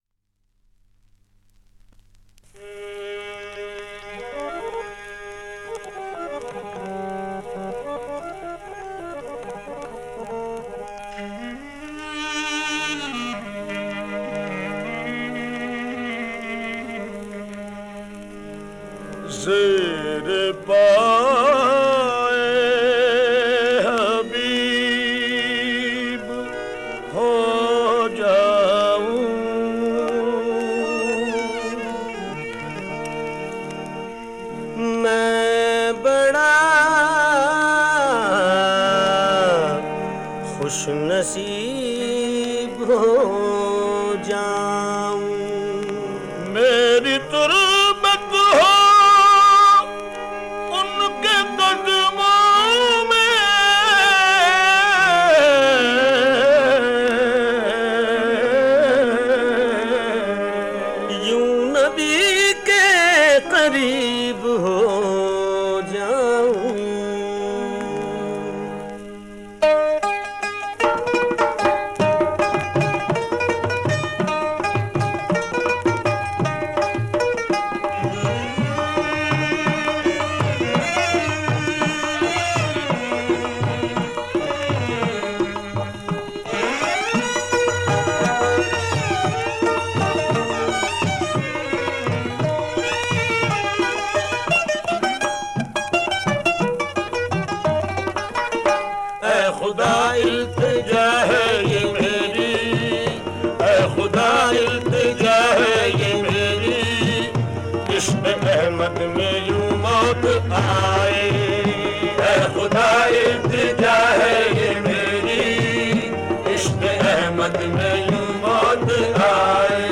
Download MP3 Worlds Largest Collection of Qawwali